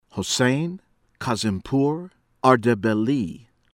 ARAGHCHI, ABBAS AB-awss   aah-RAWNG-chee